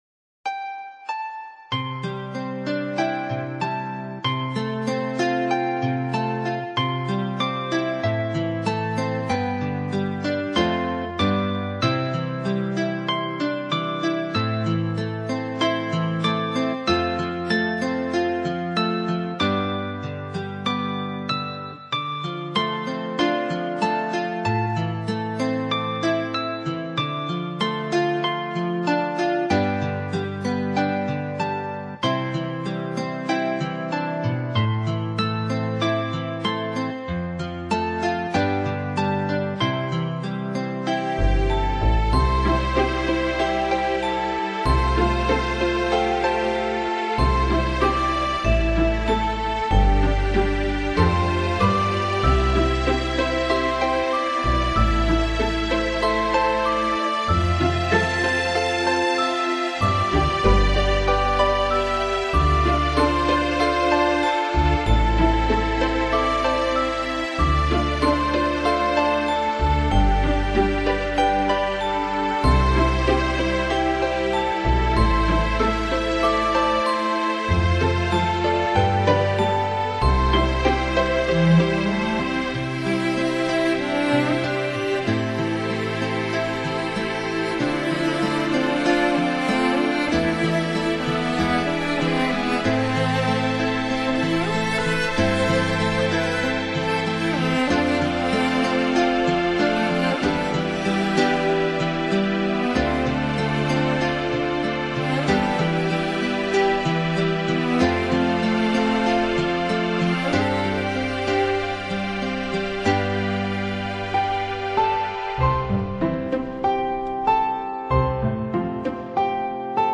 維持一貫的淡雅風格，音樂表現愈趨醇熟，